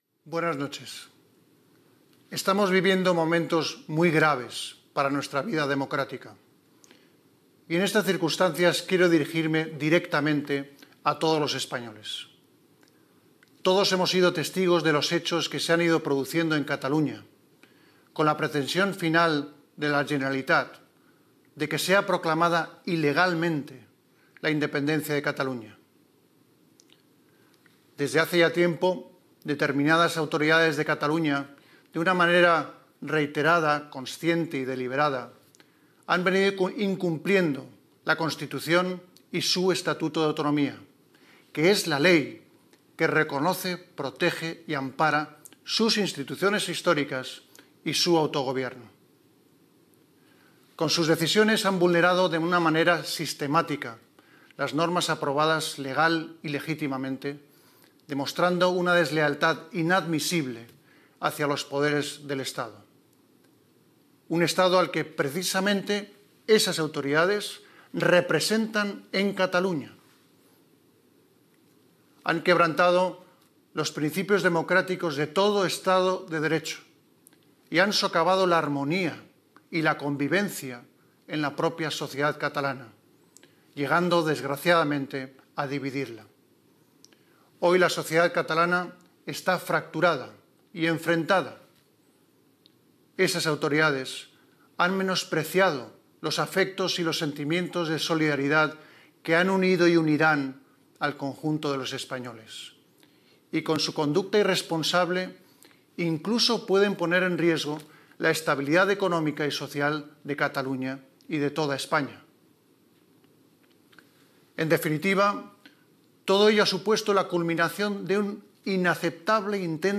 Discurs del rei d'Espanya Felipe VI acusant de deslleialtat a les autoritats de la Generalitat de Catalunya després de la celebració de Referpendum unilateral d'autodeterminació de Catalunya el dia 1 d'octubre de 2017
Informatiu